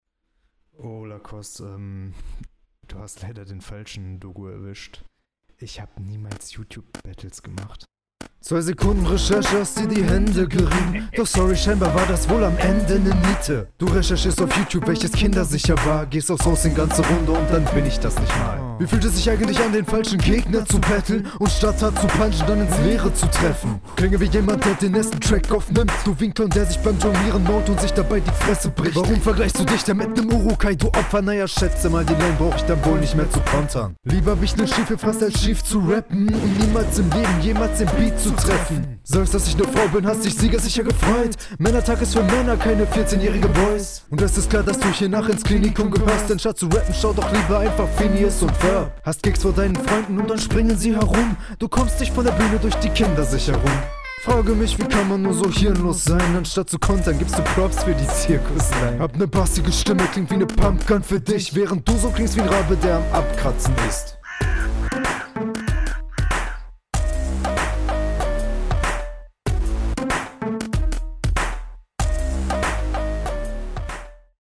Flow fand ich stark, Konter auch cool. weiter machen!